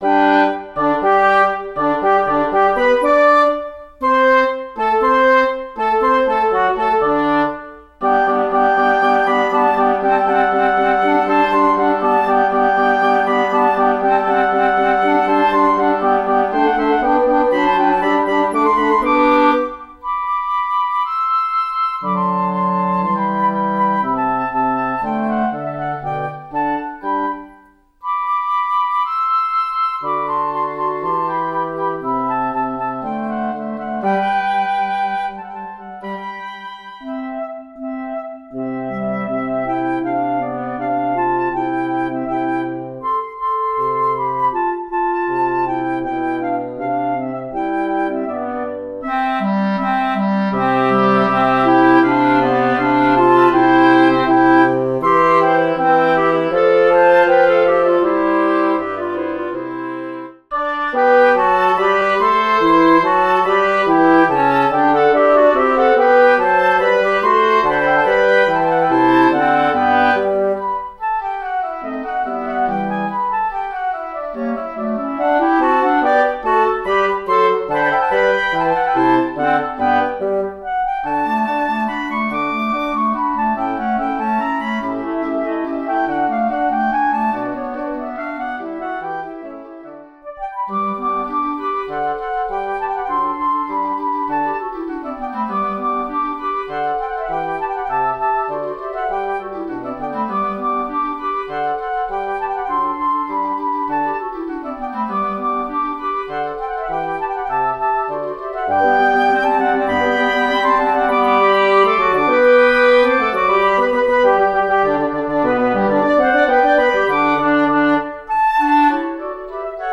Voicing: Woodwind Quartet